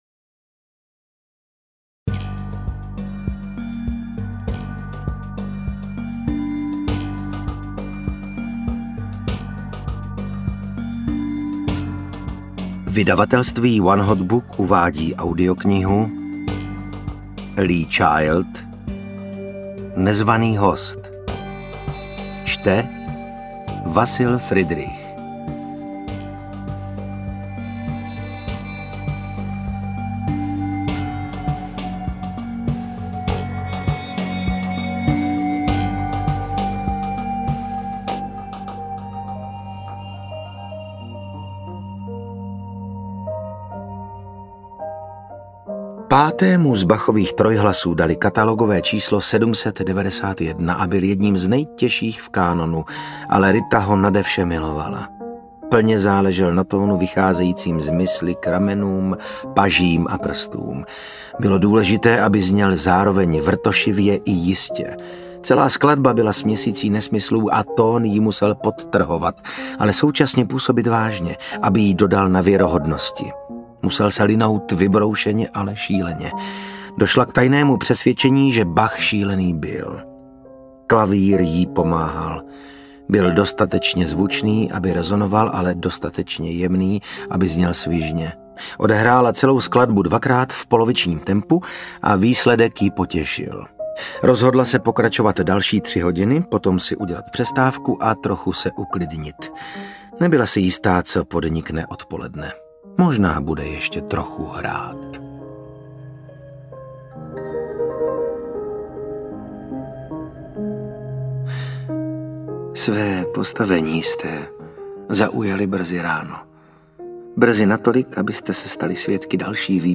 Jack Reacher: Nezvaný host audiokniha
Ukázka z knihy
• InterpretVasil Fridrich